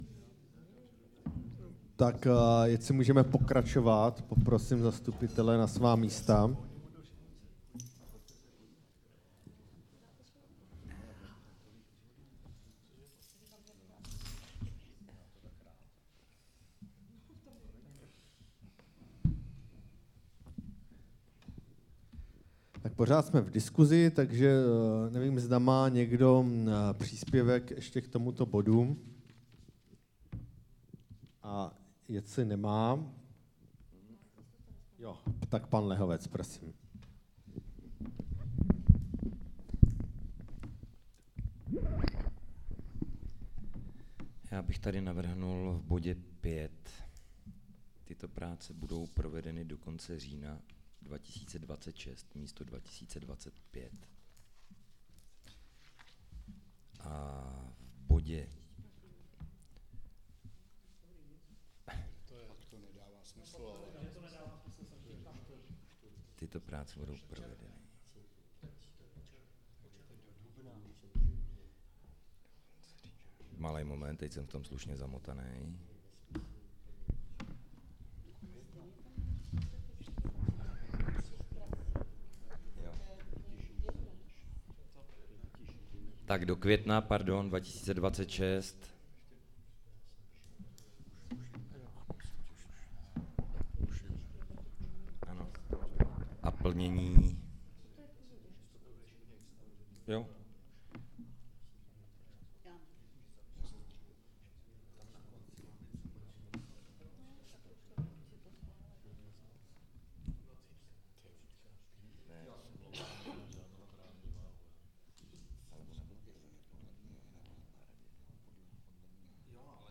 21. veřejné zasedání ZMČ